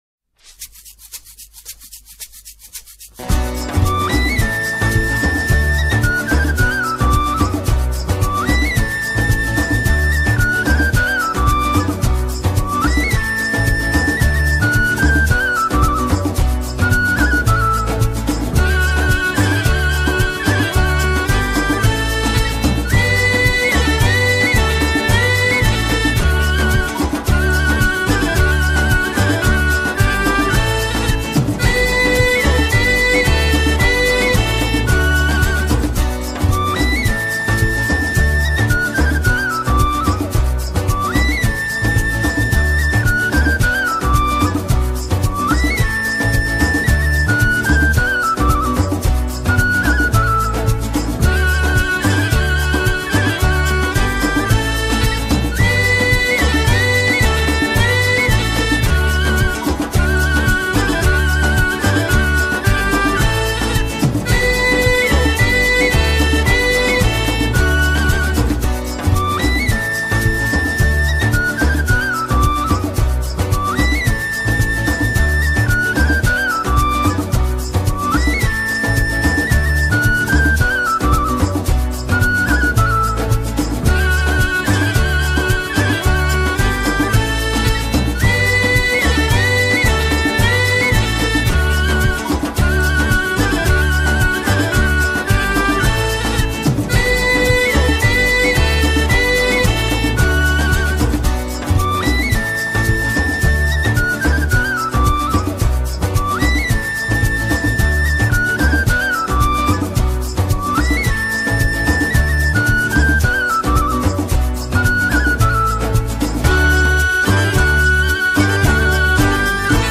Fotorelacja i nie tylko ze spotkania kolędowego z cyklu ”Piernikową nutą”
ŚWIEĆ-GWIAZDECZKO-MAŁA-ŚWIEĆ-KARAOKE.mp3